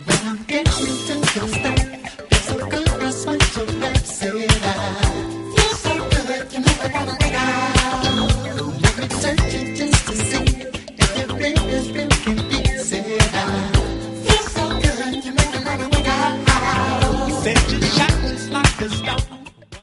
disco/funk